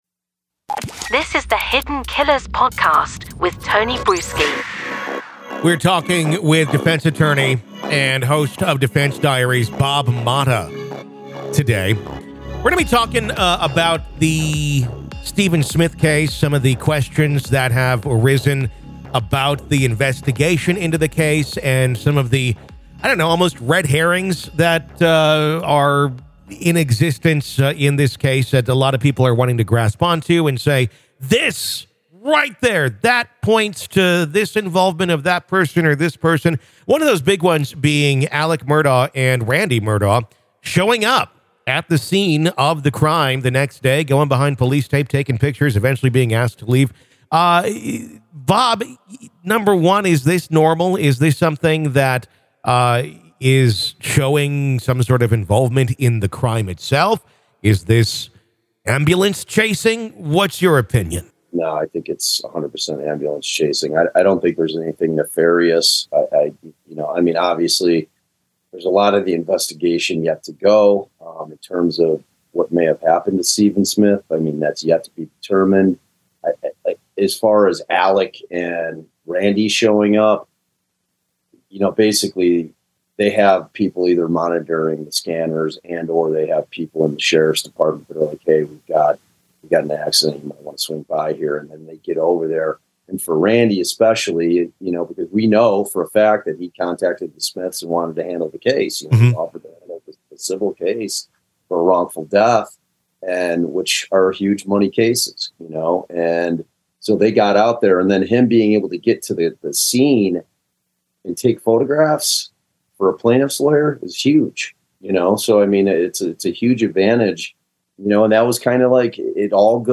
The interview sheds light on the case and gives viewers an understanding of the legal and ethical responsibilities of witnesses in criminal investigations.